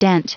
Prononciation audio / Fichier audio de DENT en anglais
Prononciation du mot dent en anglais (fichier audio)